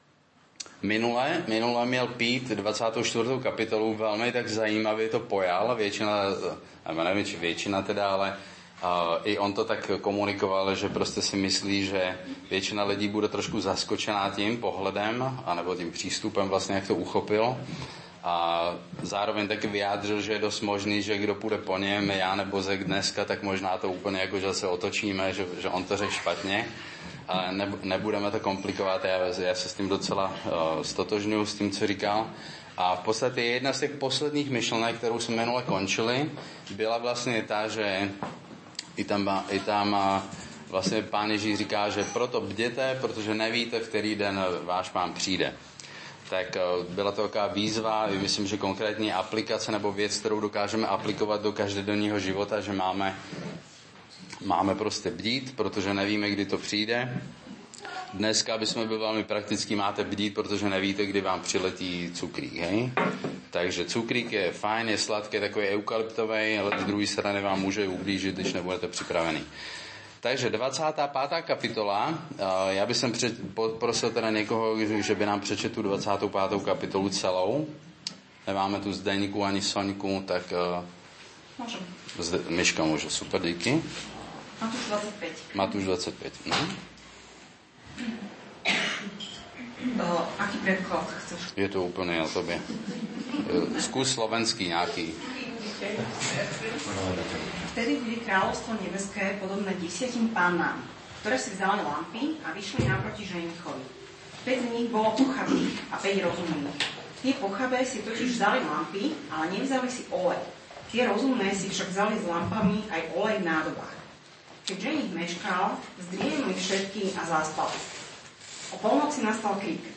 Nahrávka kázne Kresťanského centra Nový začiatok z 21. júla 2019